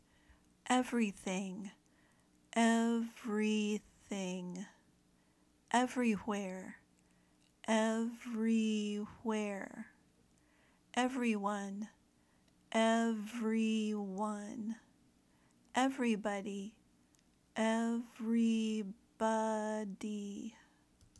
When you say the word every, the second “e” is not pronounced. Native speakers say “EV-ree.” The stress is on the first syllable, “EV.”
EV-ry-where
EV-ry-thing
EV-ry-one
EV-ry-bo-dy
Pronounce “Everything,” “Everywhere,” “Everyone,” and “Everybody”